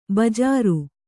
♪ bajāri